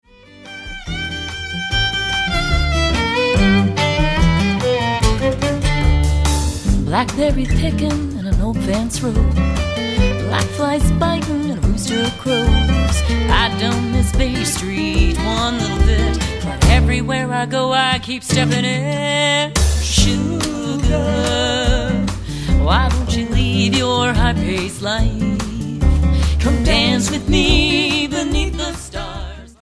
lead vocals, guitars, national steel, harmony vocals
violin
upright bass
percussion